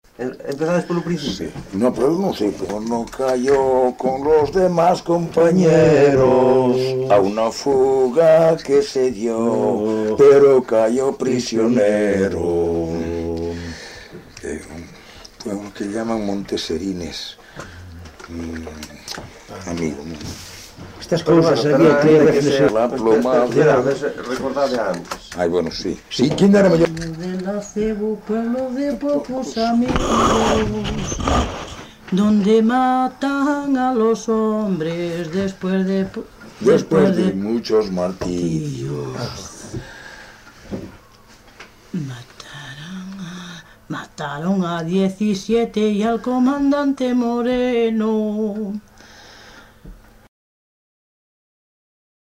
LITERATURA E DITOS POPULARES > Cantos narrativos
Lugar de compilación: Fonsagrada, A - Fonfría (Santa María Madanela) - Fonfría
Soporte orixinal: Casete
Datos musicais Refrán
Instrumentación: Voz
Instrumentos: Voz masculina